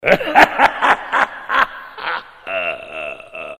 • Качество: 320, Stereo
злые
страшные
Недобрый мужской смех для оповещений